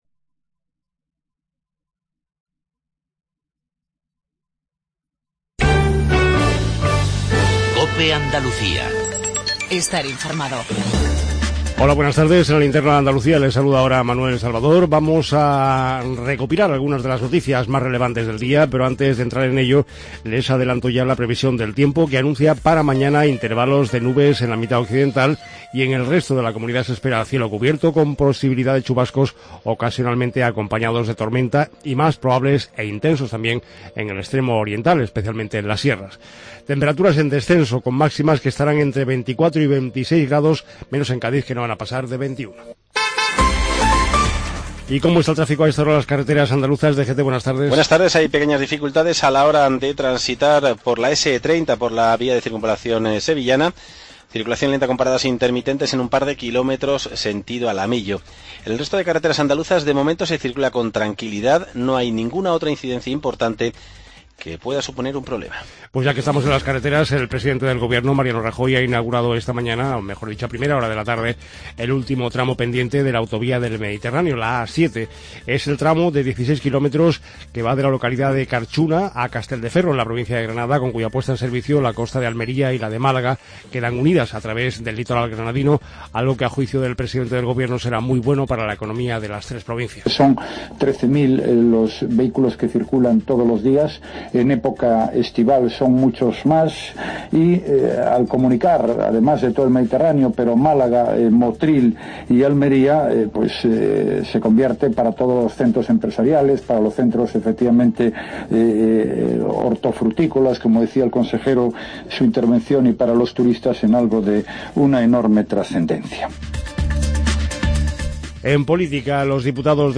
INFORMATIVO REGIONAL TARDE COPE ANDALUCIA